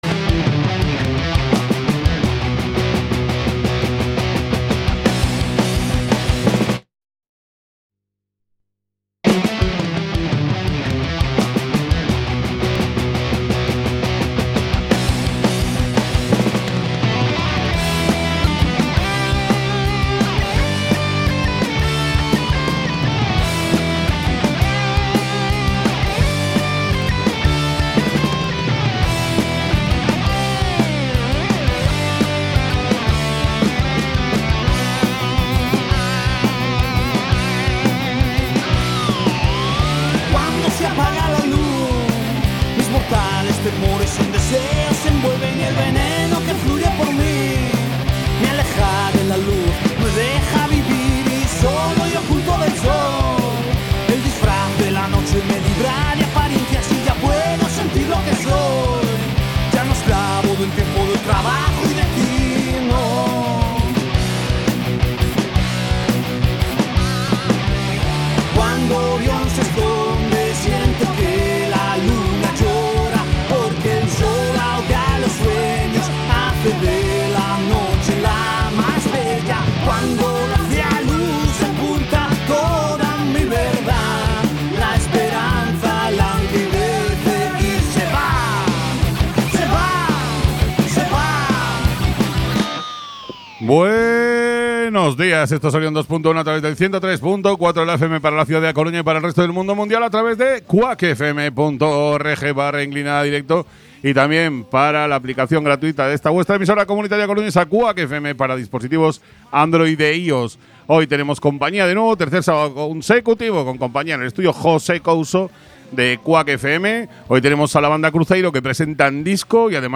Programa de Rock y heavy en todas sus vertientes con un amplio apartado de agenda, de conciertos y eventos, en la ciudad y Galicia. Entrevistas, principalmente a bandas gallegas, y repleto de novedades discográficas.